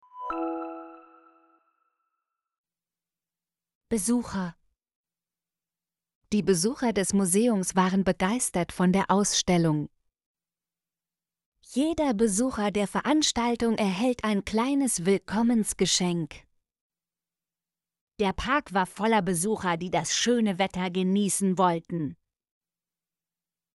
besucher - Example Sentences & Pronunciation, German Frequency List